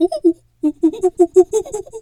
pgs/Assets/Audio/Animal_Impersonations/monkey_2_chatter_09.wav at master
monkey_2_chatter_09.wav